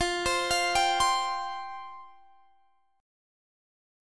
Listen to Fsus2 strummed